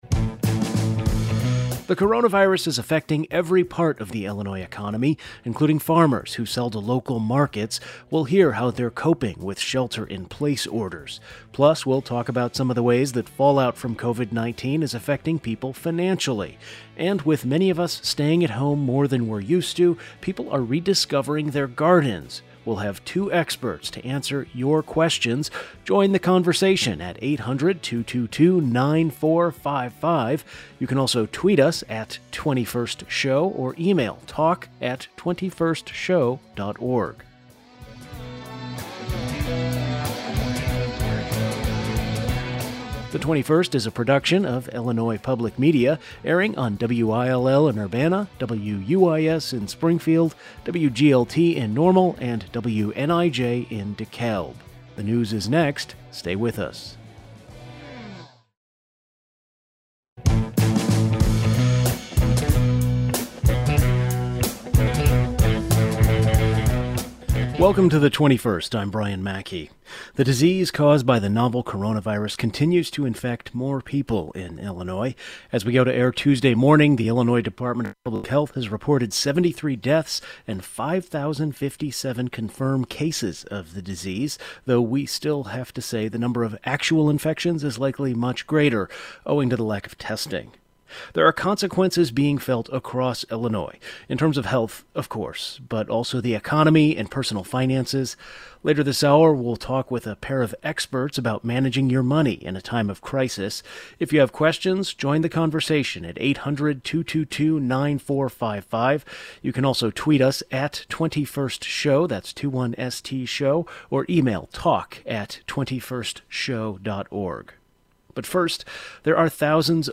We had two experts to answer your questions.